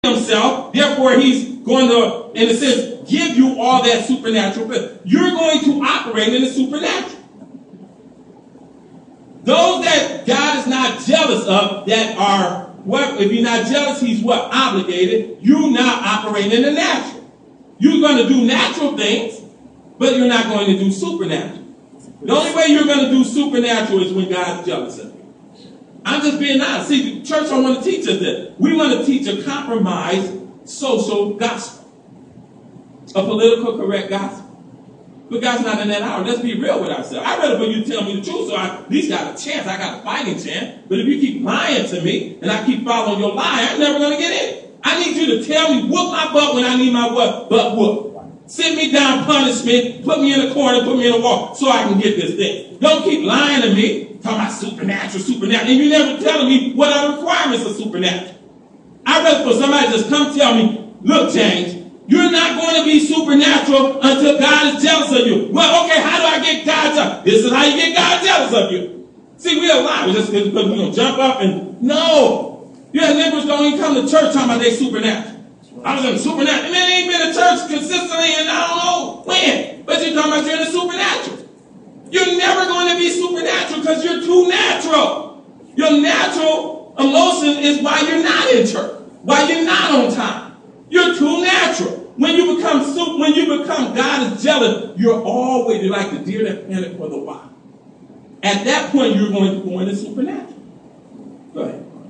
Listen to Samples of Audio Sermons